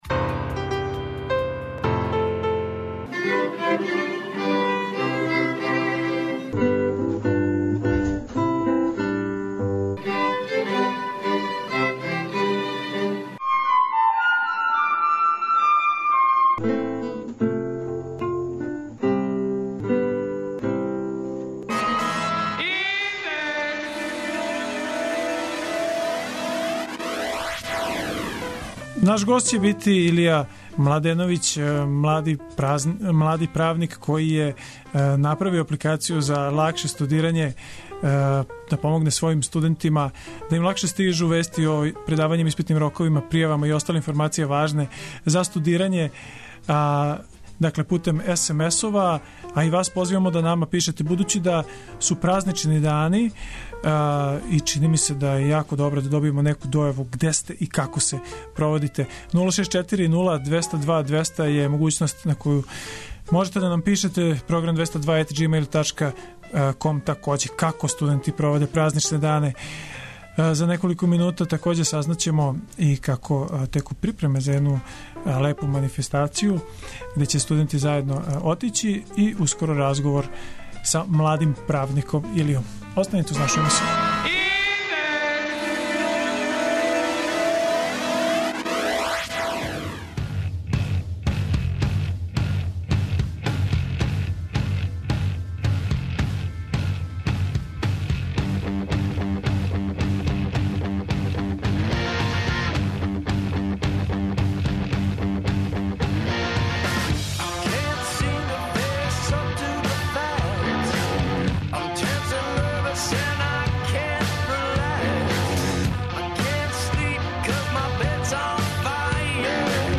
Причамо са младим правницима који су направили апликацију за лакше студирање.